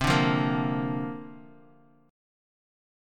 C7sus2sus4 chord